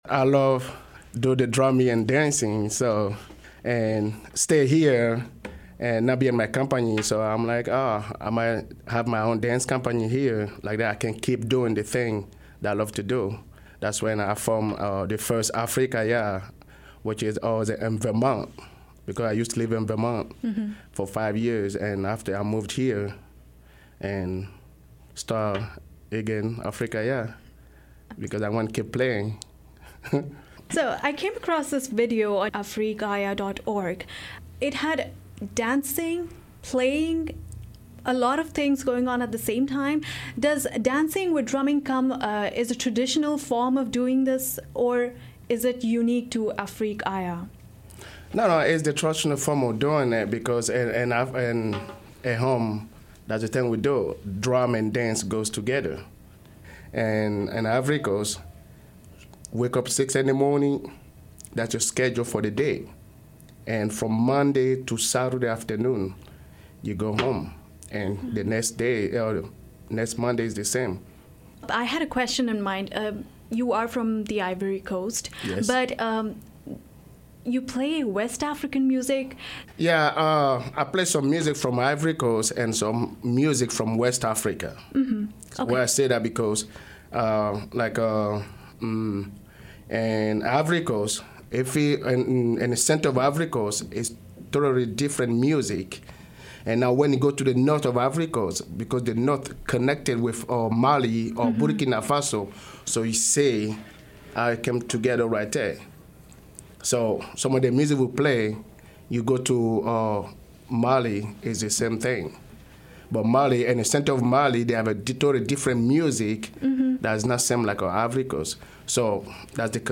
Drums in Firmin-Garner Drums in Firmin Garner.mp3 The West African inspired drumming and dancing group Afrique Aya visit the Firmin-Garner Performance Studio and show us how the "telephone of Africa" works.
Drums in Firmin Garner.mp3